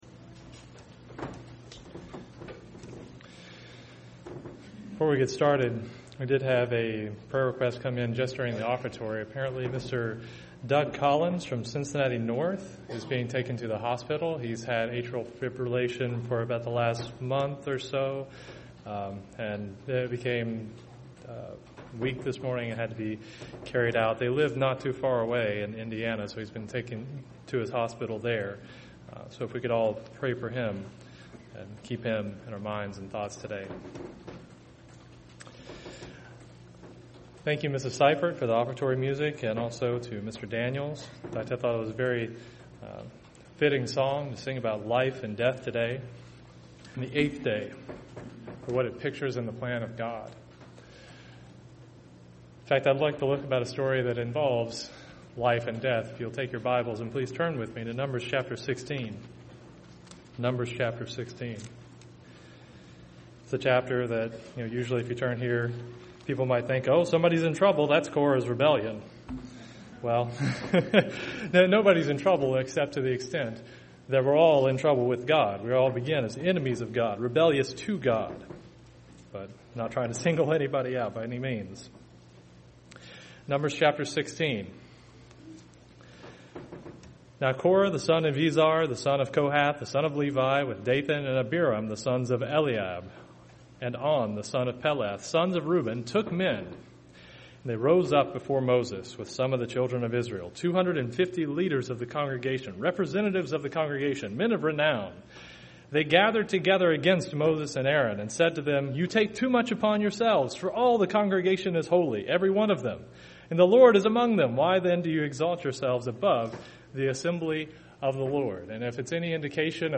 This sermon was given at the Cincinnati, Ohio 2018 Feast site.